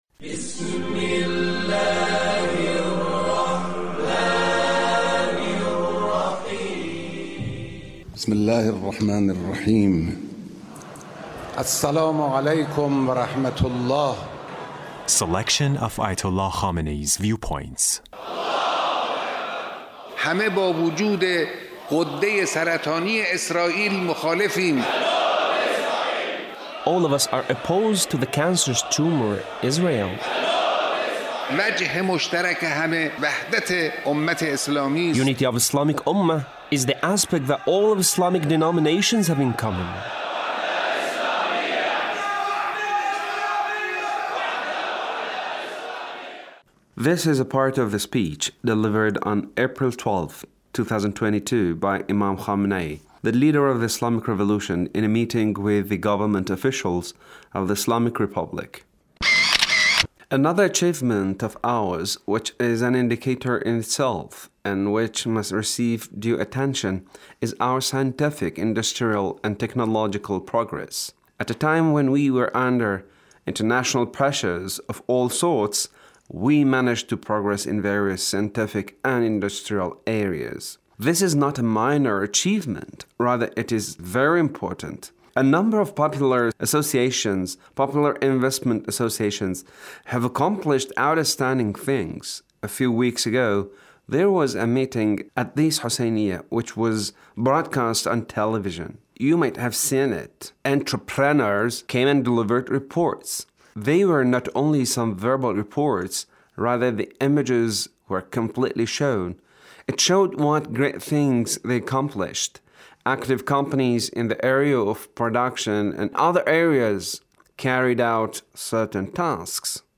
The Leader's speech in a meeting with Government Officials